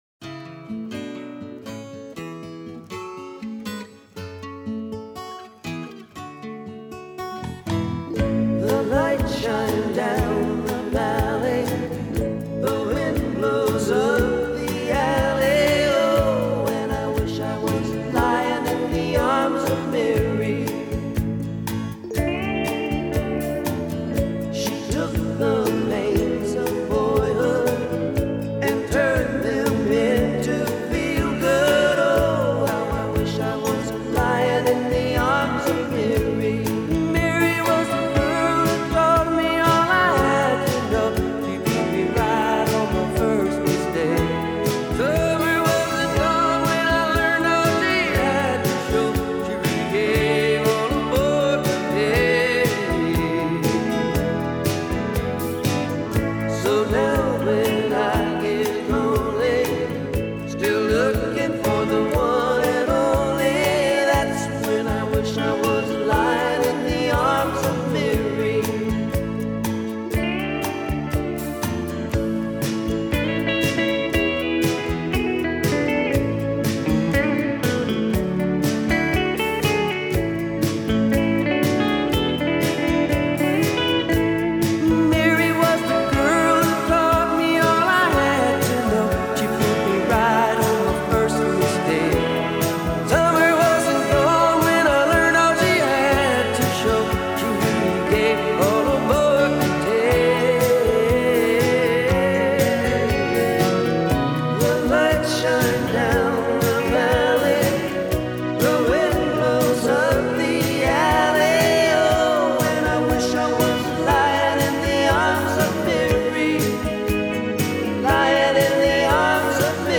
a great pop/country album